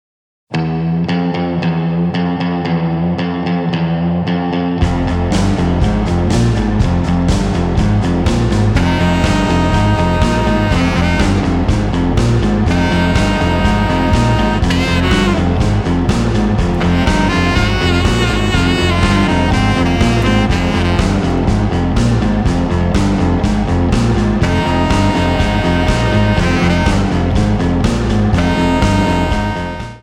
Tonart:F Multifile (kein Sofortdownload.
Die besten Playbacks Instrumentals und Karaoke Versionen .